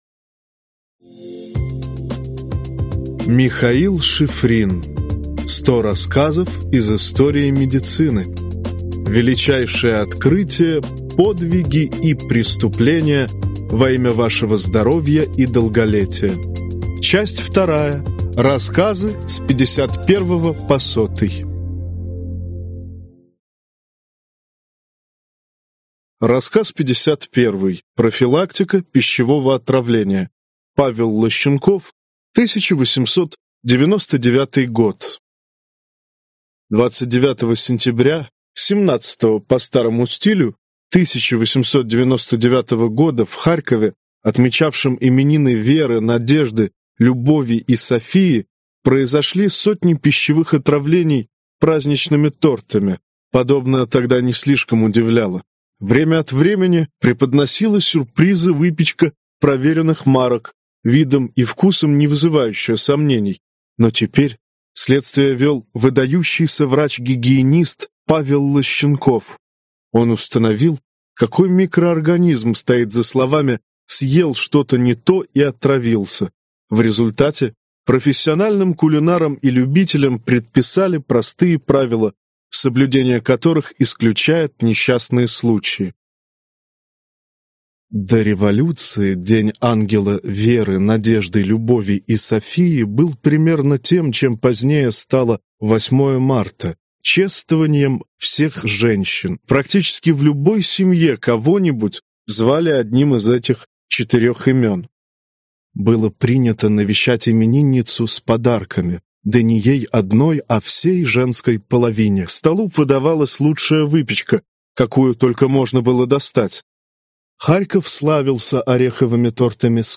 Аудиокнига 100 рассказов из истории медицины. Часть 2 (рассказы с 51 по 100) | Библиотека аудиокниг